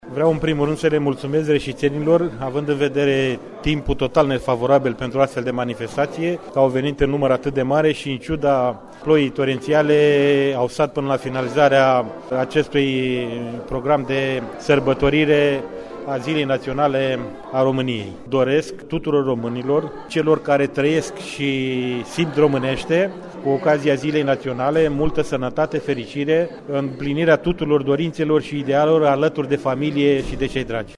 După intonarea imnului de stat, ceremonia a continuat cu discursul oficialităţilor, mai precis al prefectulului judeţului Caraş-Severin și al viceprimarului municipiului Reşiţa, Ioan Crina, dar din staff au făcut parte secretarul de stat Silviu Hurduzeu, ofiţeri activi şi în rezervă în armata României, conducătorii structurilor din MAI, parlamentari şi oameni politici.
Reprezentantul Guvernului în teritoriu, Nicolae Miu Ciobanu a transmis tuturor urări de sănătate şi Mulţi ani!